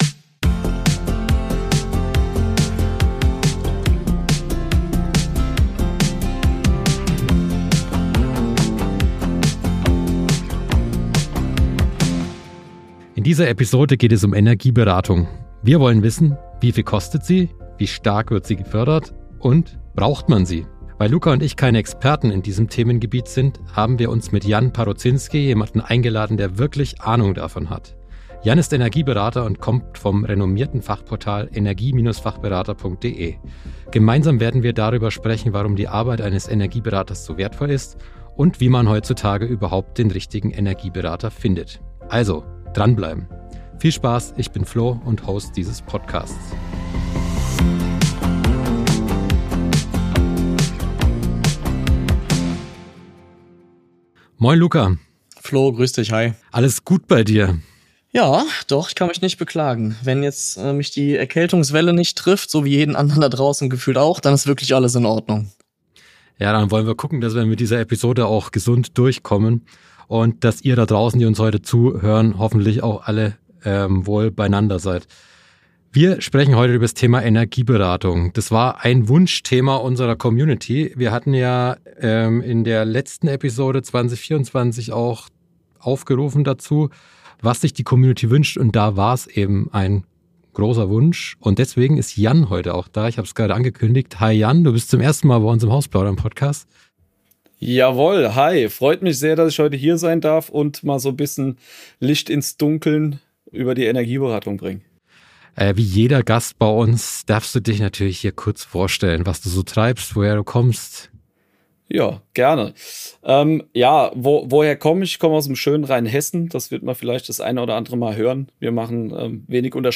Die drei sprechen über die Arbeit eines Energieberaters, wie wertvoll sie ist und wie man heutzutage überhaupt den richtigen Energieberater findet.